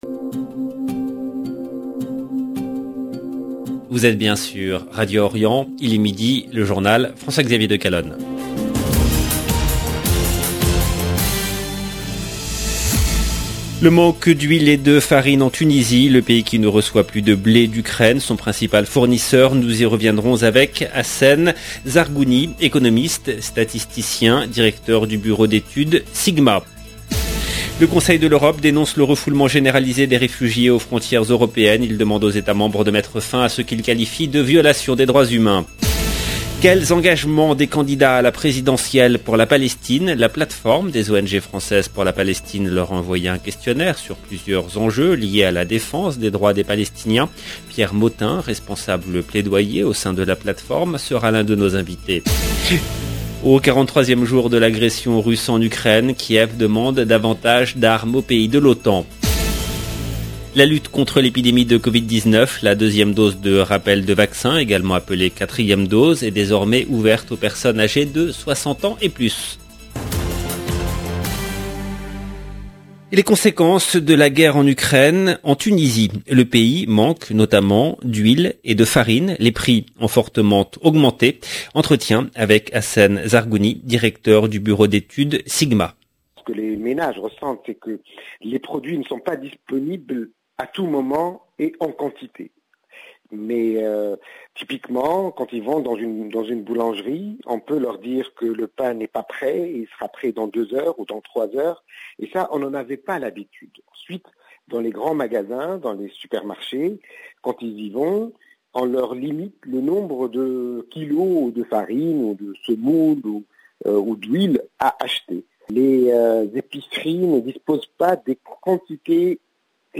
EDITION DU JOURNAL DE 12 H EN LANGUE FRANCAISE DU 7/4/2022